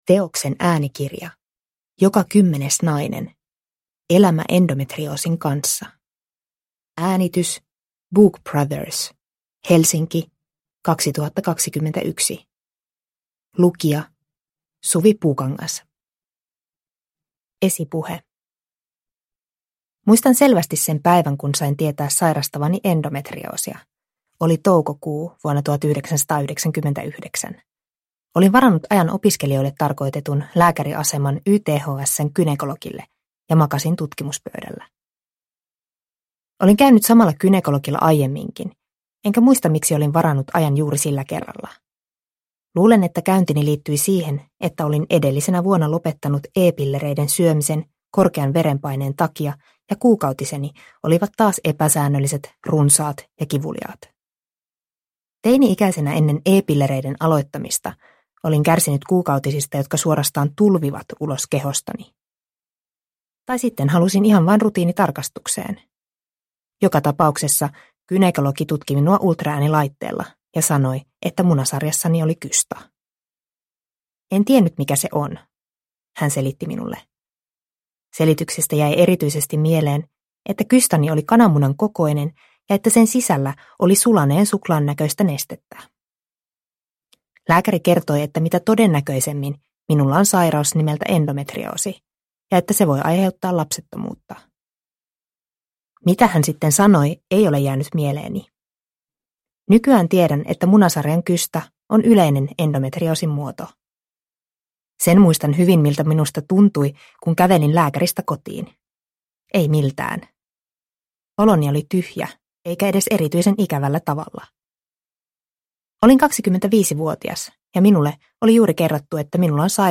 Joka kymmenes nainen – Ljudbok – Laddas ner